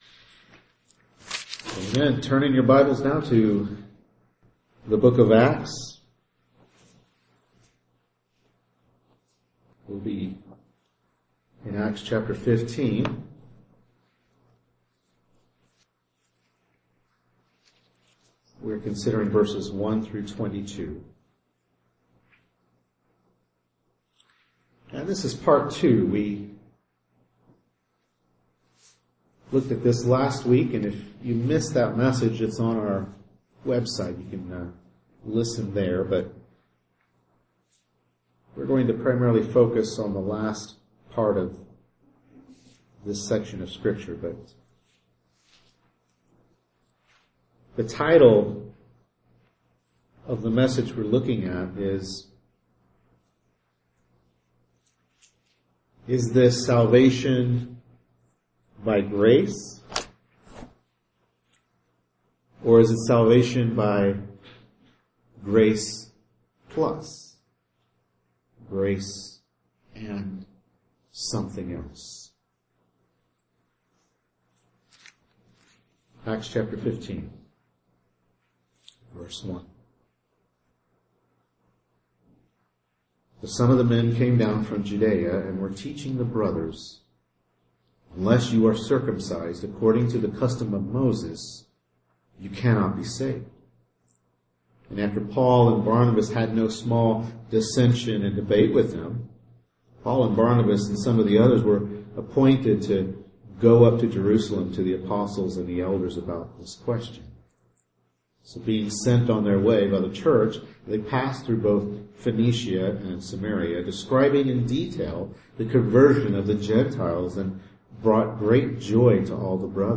Acts 15:1-22a Service Type: Morning Worship Service Bible Text